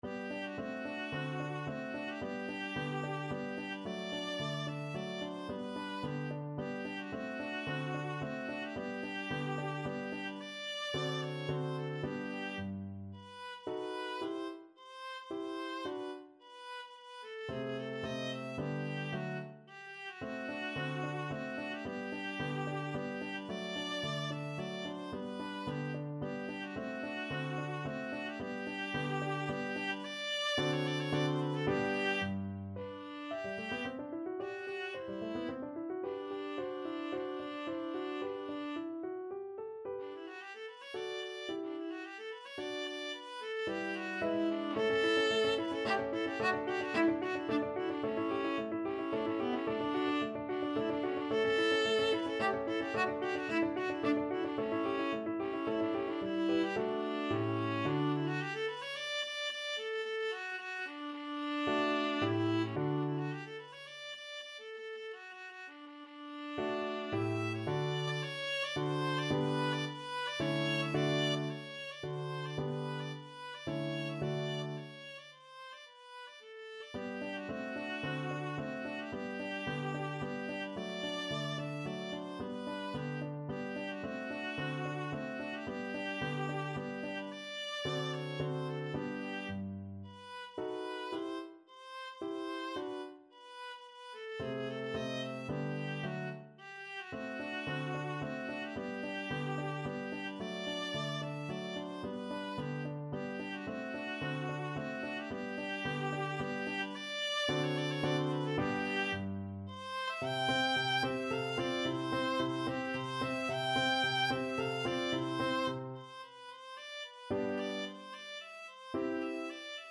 Free Sheet music for Viola
Viola
G major (Sounding Pitch) (View more G major Music for Viola )
3/4 (View more 3/4 Music)
II: Tempo di Menuetto =110
F#4-A6
Classical (View more Classical Viola Music)